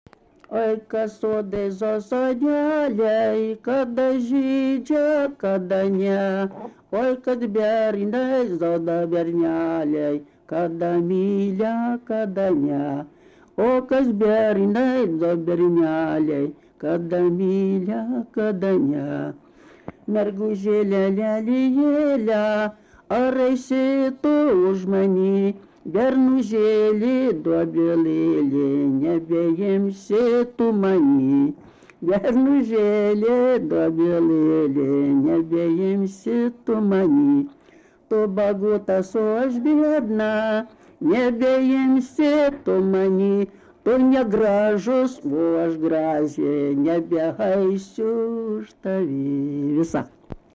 Senieji Migūčionys
vokalinis